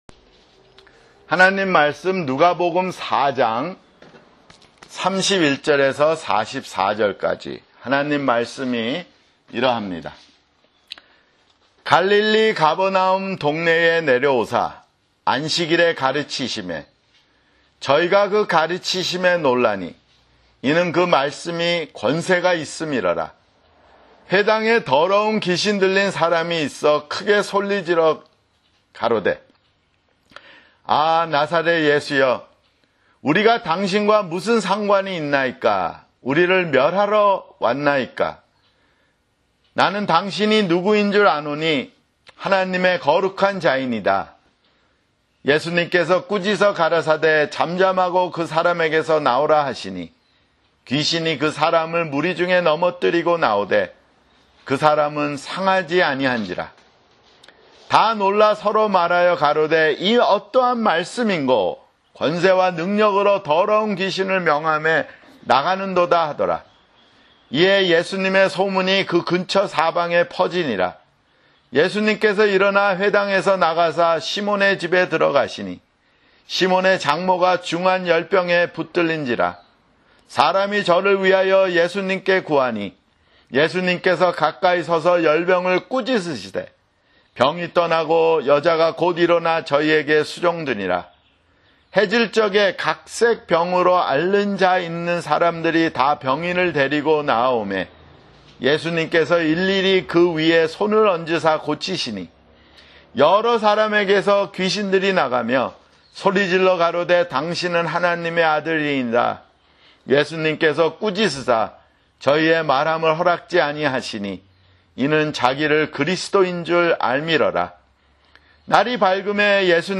[주일설교] 누가복음 (31)